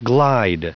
Prononciation du mot glide en anglais (fichier audio)
Prononciation du mot : glide